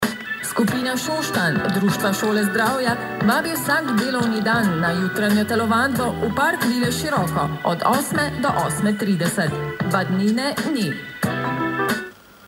Moj radio, (Celje in Velenje) - reklama za skupino Šoštanj vsak dan ob 7.45
REKLAMA-skupina Šoštanj.mp3